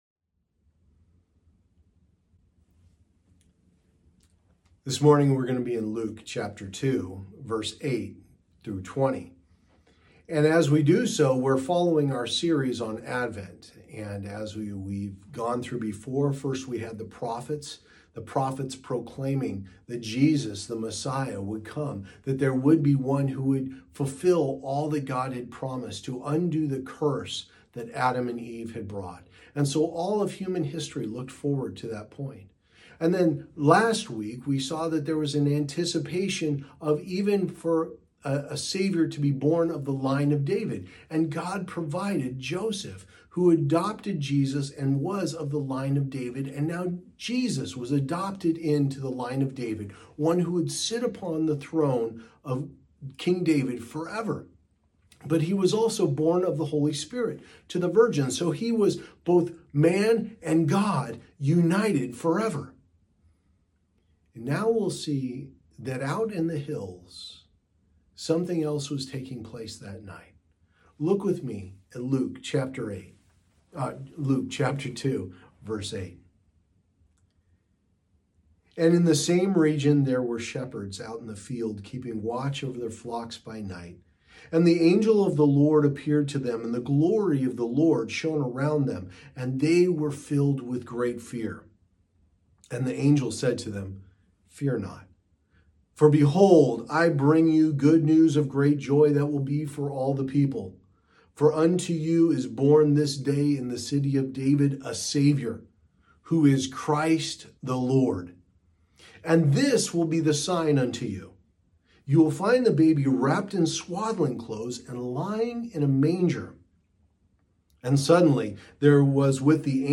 Passage: Luke 2:8-20 Services: Sunday Morning Service Download Files Notes Previous Next